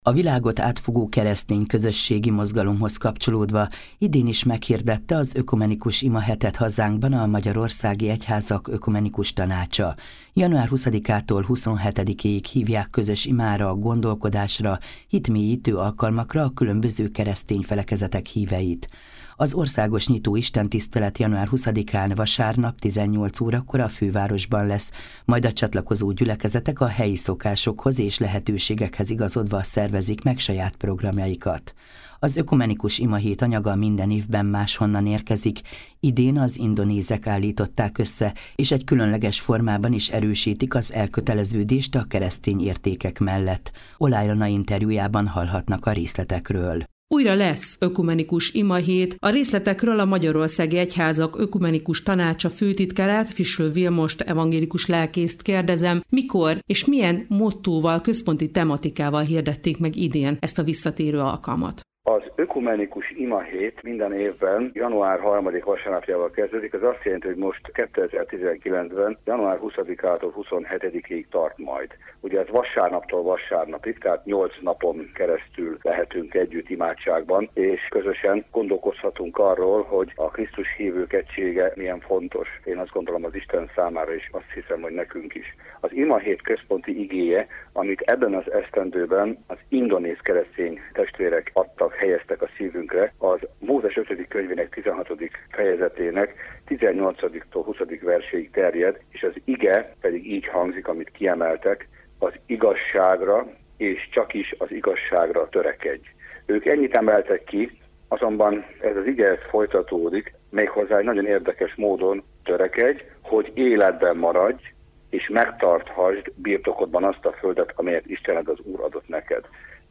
interjút az Európa Rádió ban